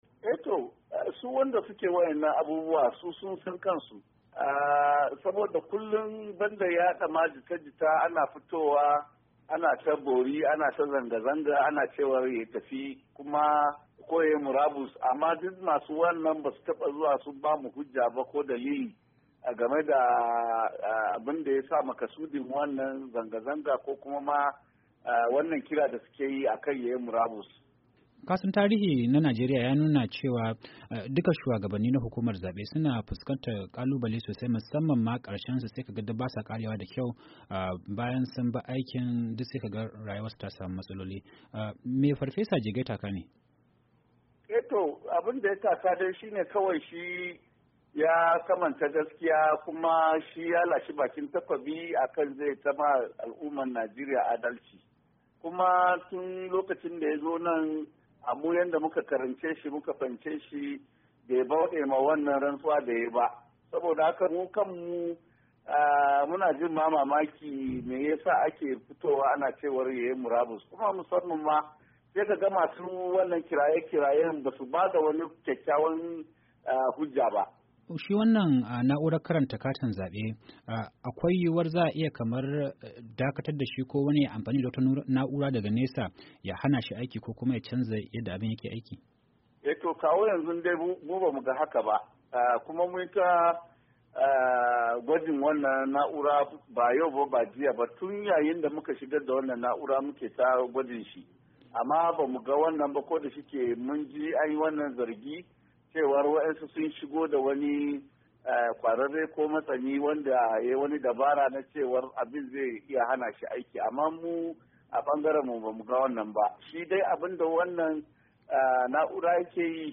A wata hira da Muryar Amurka